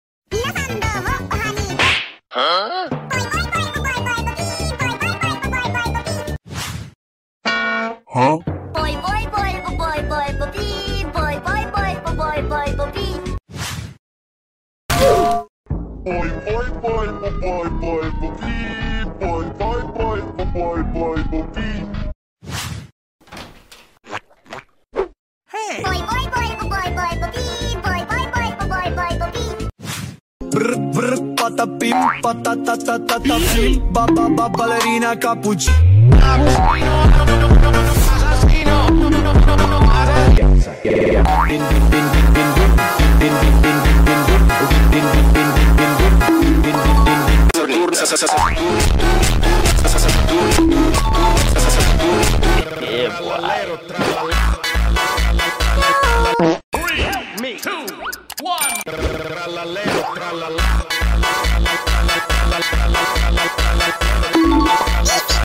cartoon for kids funny 🐼 sound effects free download